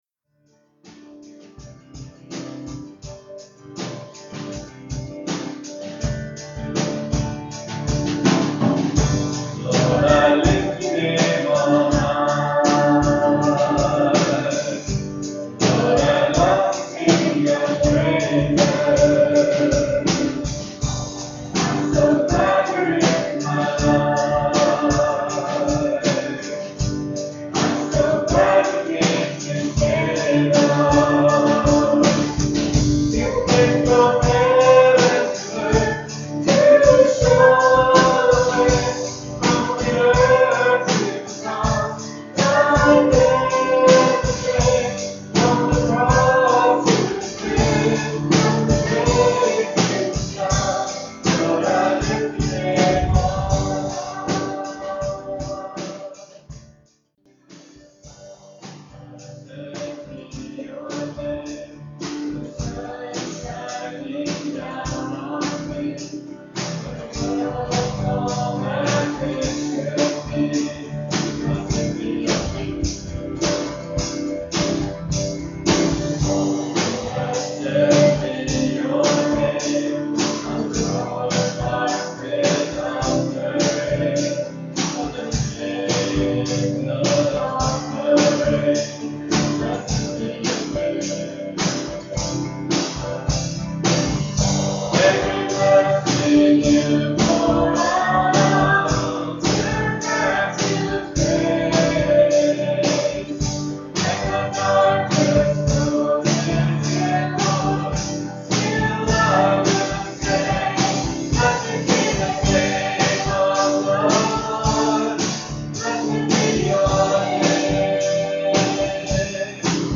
PLAY The Way of the Cross, Part 2, February 26, 2012 Scripture: Luke 9:57-62. Message
at Ewa Beach Baptist Church